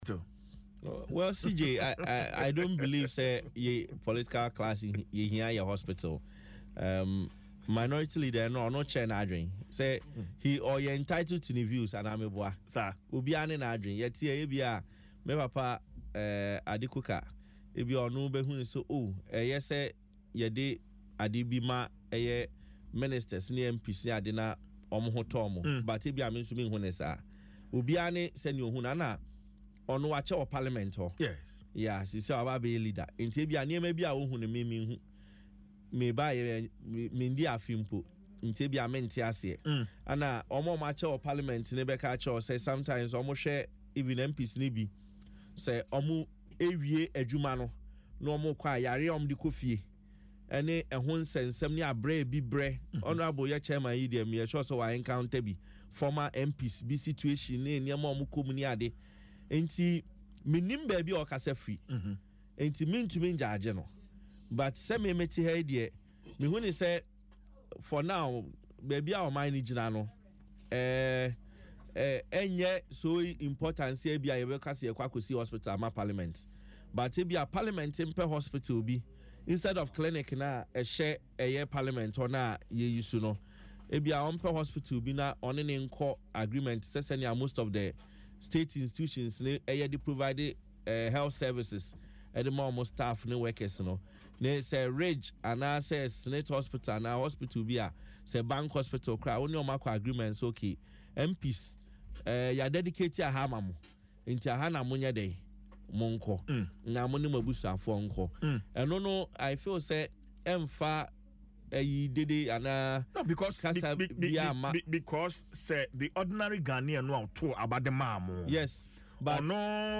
Speaking on Adom FM’s Dwaso Nsem, he described the proposal as premature, considering Ghana’s current economic challenges.